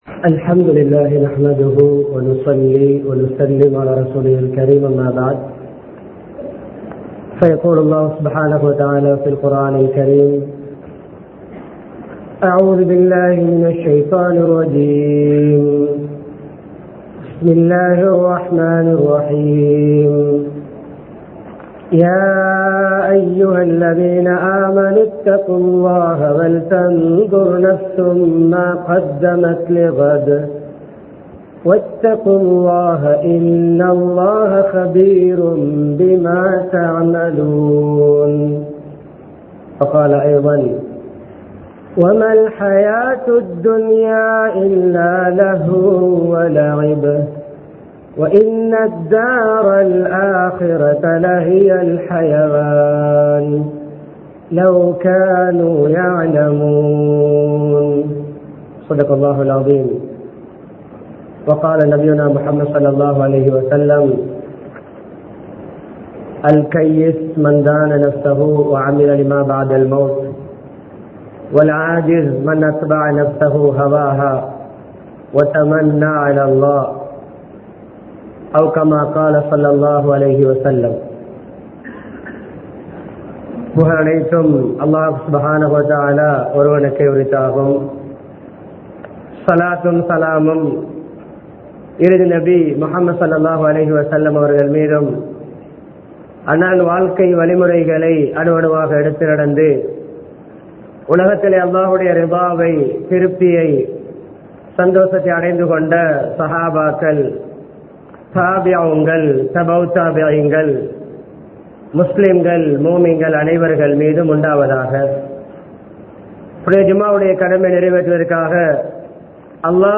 மறுமைக்கான முதலீடு | Audio Bayans | All Ceylon Muslim Youth Community | Addalaichenai
Rathmalana Jumua Masjidh